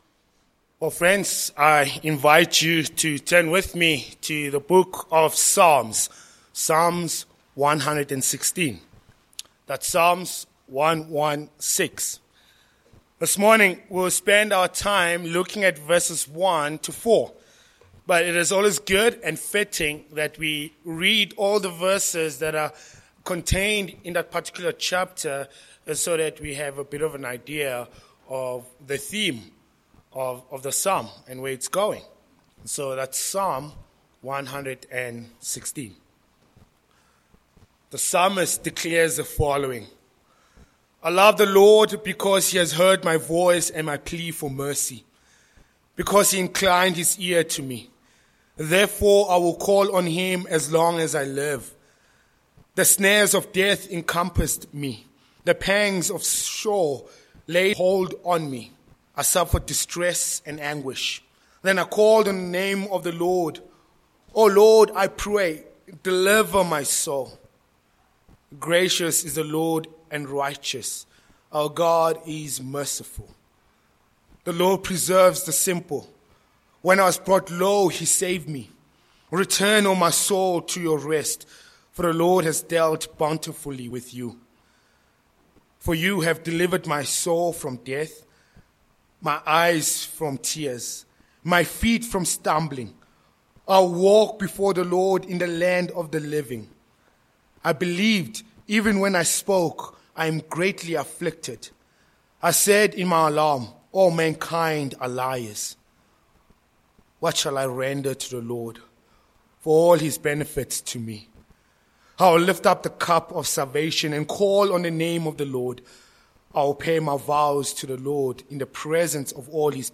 Sermon points: 1. Loving God Who Answers Prayer v1-2